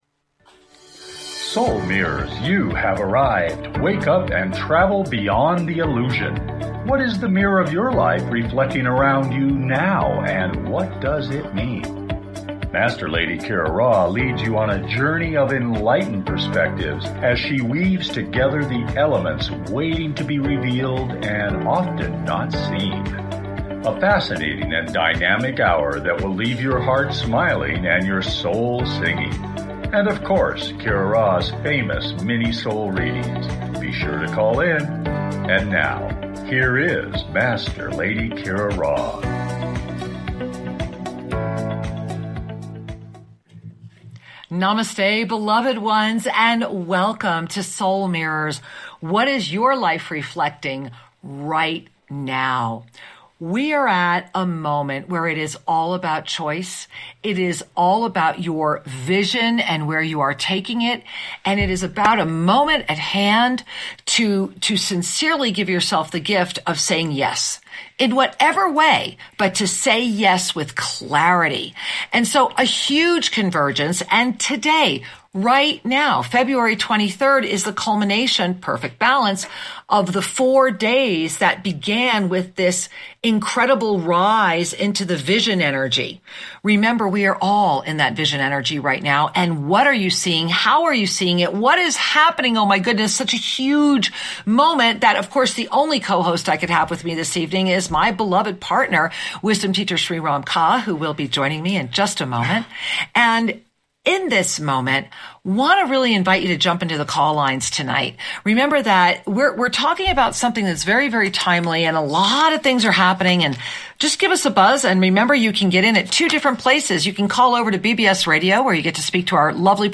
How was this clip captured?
Lively, entertaining, and refreshingly authentic, the hour goes quickly!